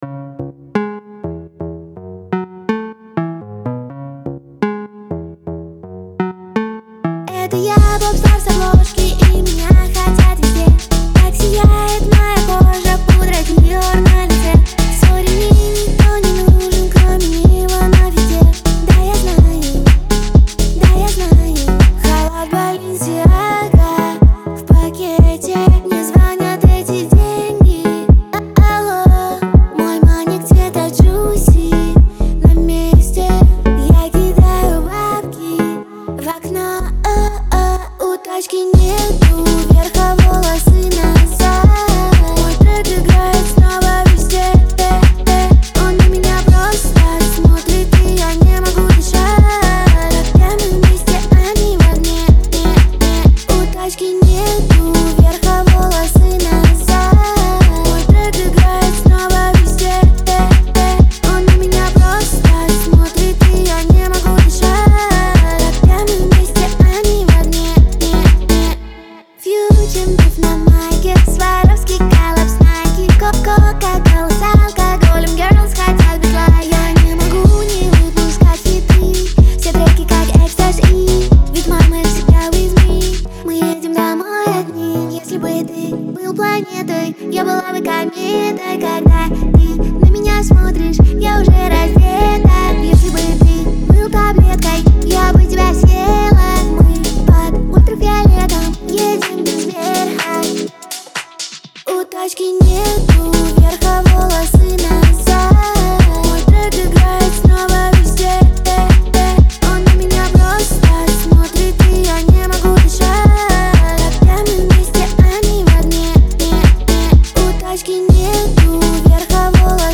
Категории: Русские песни, Поп.